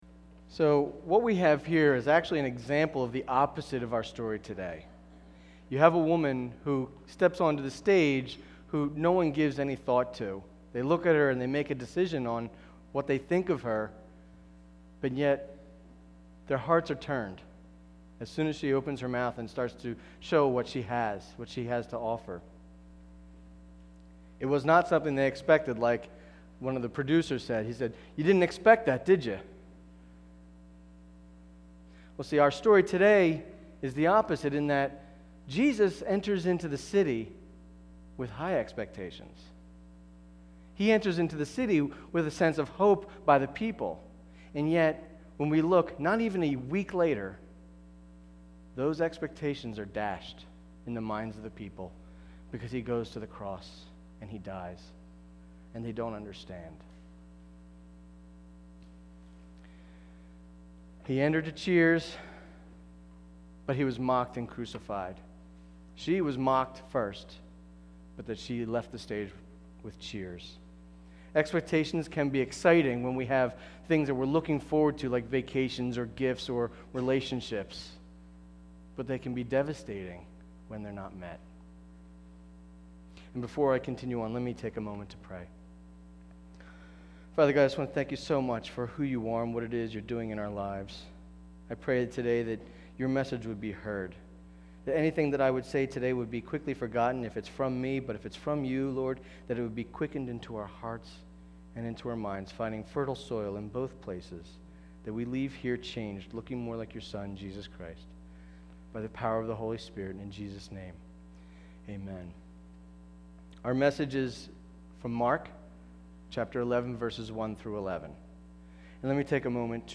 Palm Sunday 2011